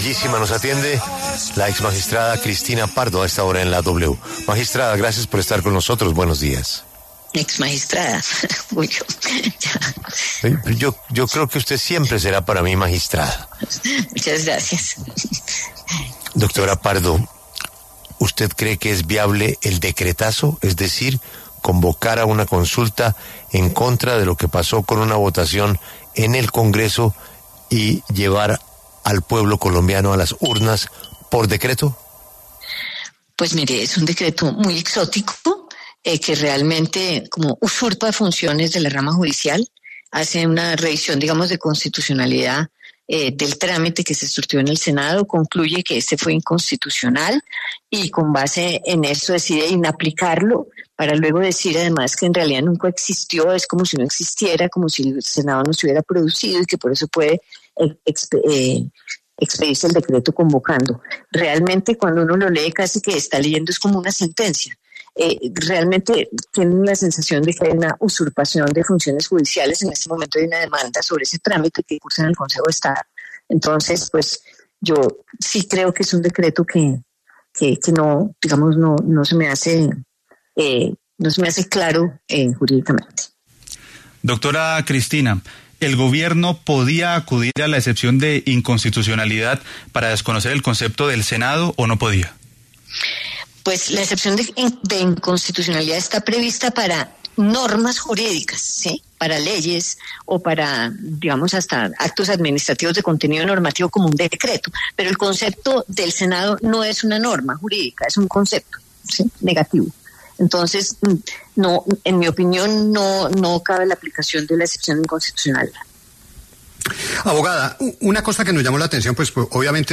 La exmagistrada de la Corte Constitucional, la abogada Cristina Pardo dio su opinión ante los micrófonos de La W, sobre el polémico decreto que firmo el presidente Gustavo Petro sobre la consulta popular.